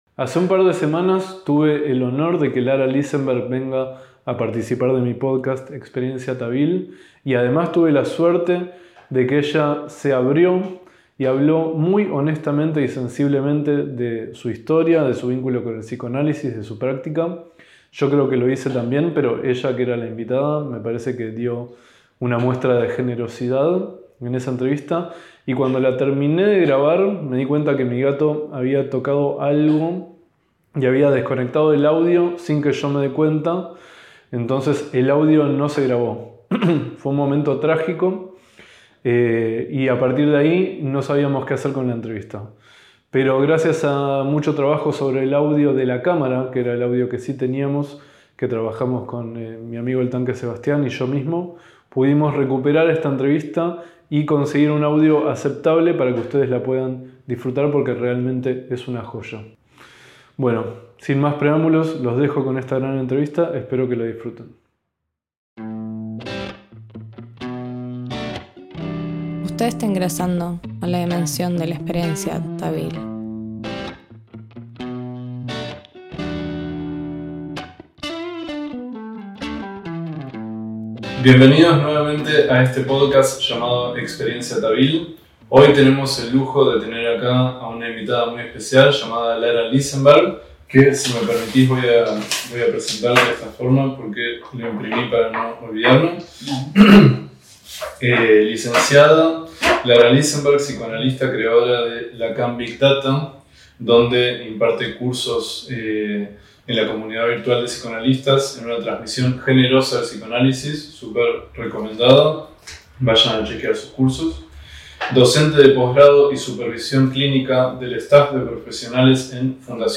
¡Una entrevista que de milagro no se perdió por razones técnicas!